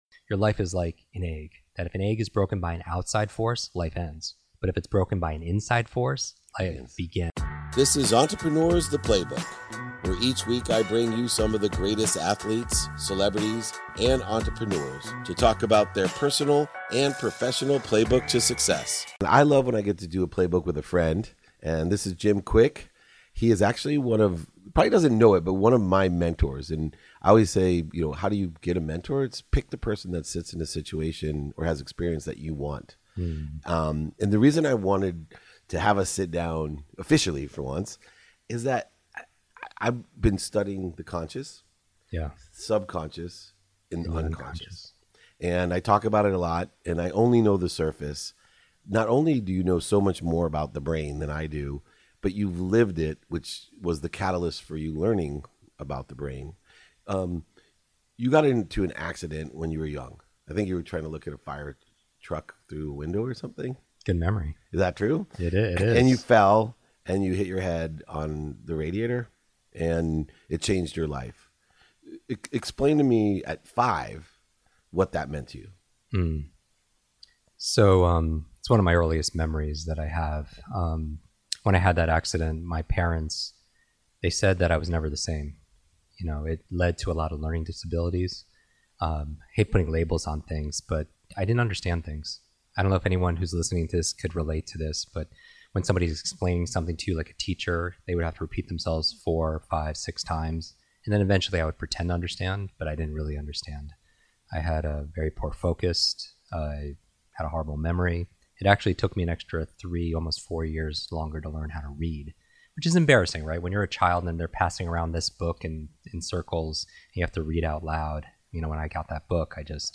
Today’s episode is from a conversation I had back in 2019 with Jim Kwik, America's renowned brain coach, and founder and CEO of Kwik Learning. In this enlightening episode, we dive deep into the intricacies of optimizing our brains, unraveling the secrets behind accelerated learning and improved memory. Jim, also a successful podcaster, writer, and entrepreneur, shares invaluable insights and actionable strategies to unlock our full cognitive potential.